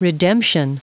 Prononciation du mot redemption en anglais (fichier audio)
Prononciation du mot : redemption